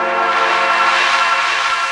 P A D61 02-L.wav